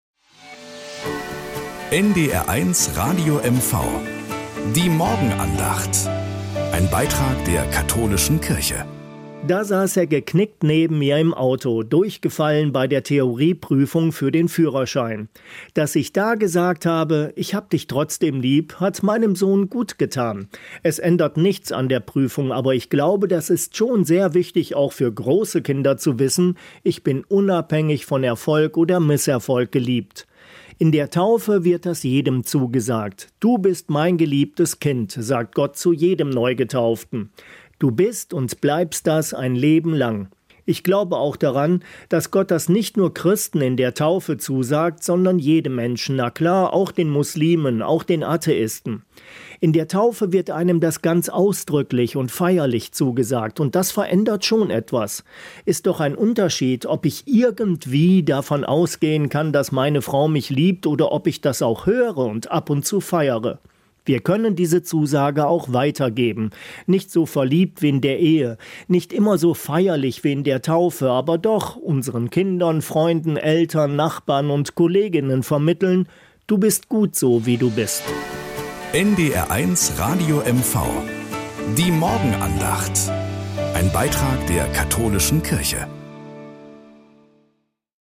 Morgenandacht auf NDR 1 Radio MV
Um 6:20 Uhr gibt es in der Sendung "Der Frühstücksclub" eine